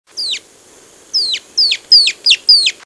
Myiozetetes similis (social flycatcher)
Social Flycatchers (Myiozetetes similis) often nest around people. In the Tortuguero area (Costa Rica), where this one was recorded, the nests can be seen hanging from telephone wires and poles.